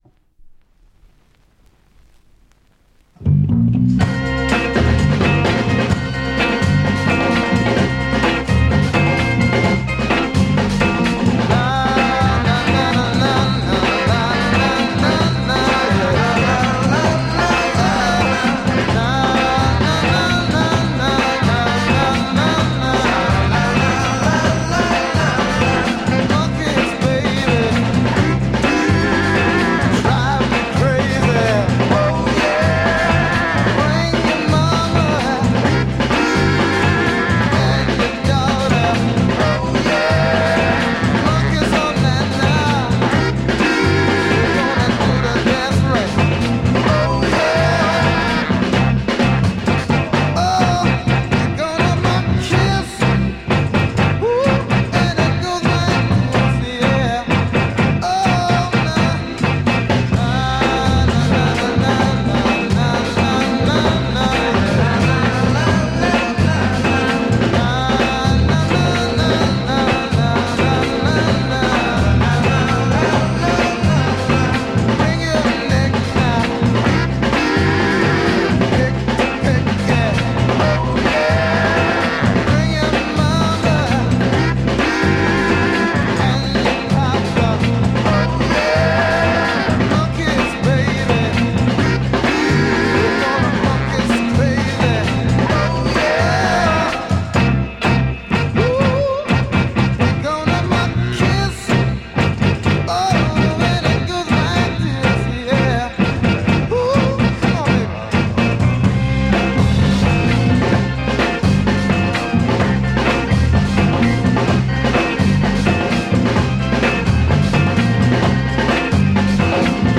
Killer Soul Break Mod